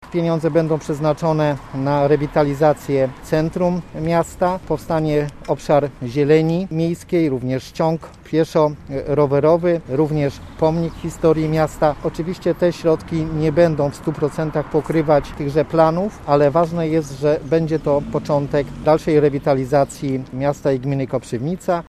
Mówi poseł Marek Kwitek: